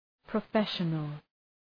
{prə’feʃənəl}